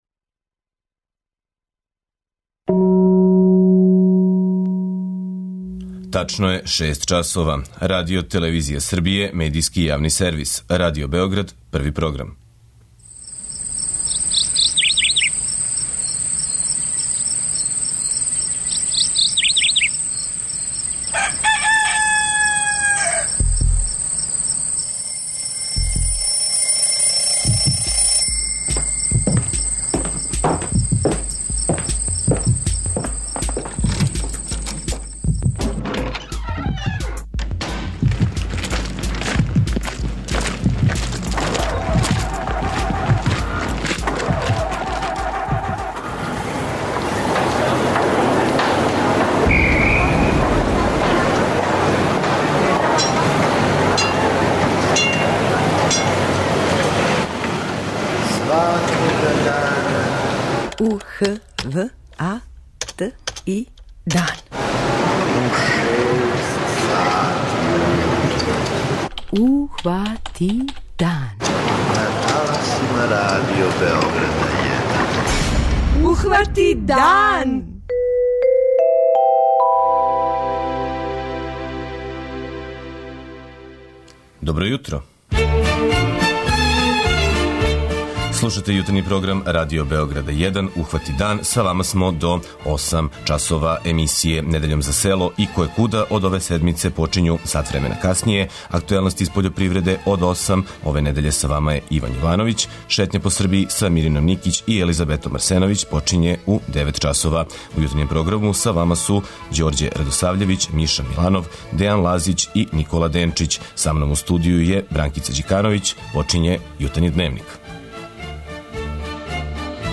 преузми : 57.32 MB Ухвати дан Autor: Група аутора Јутарњи програм Радио Београда 1!